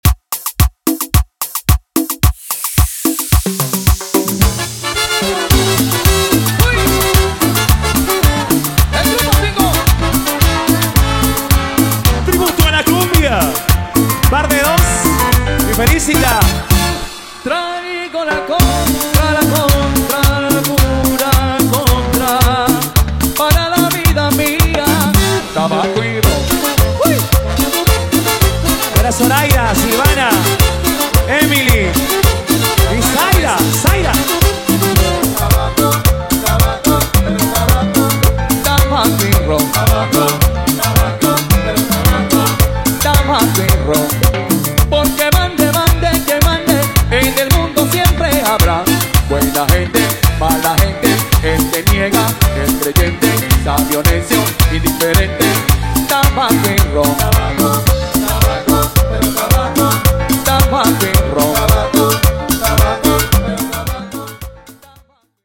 cumbia remix